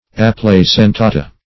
Search Result for " aplacentata" : The Collaborative International Dictionary of English v.0.48: Aplacentata \Ap`la*cen*ta"ta\, n. pl.